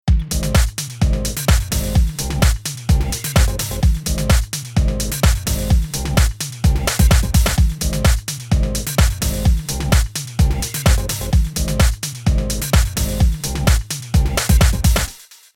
Basic loop dry